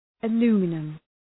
Προφορά
{ə’lu:mənəm}